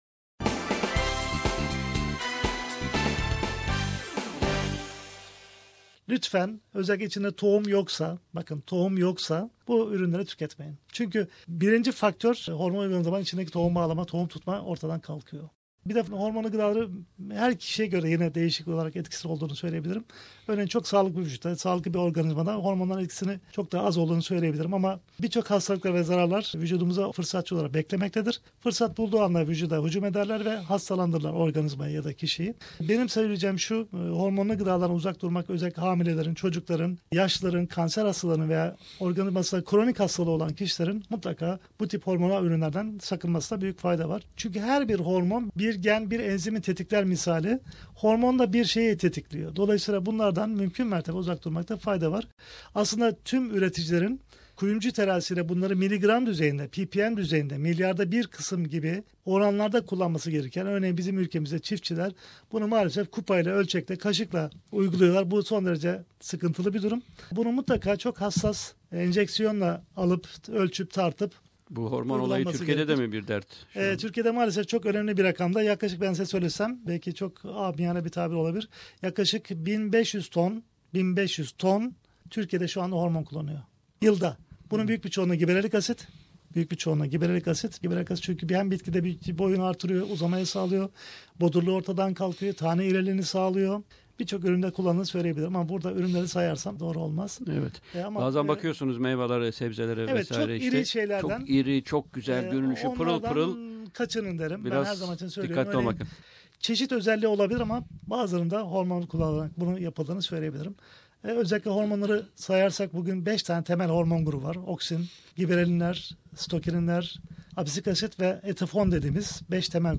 'Part two' of the interview is on hormonal foods.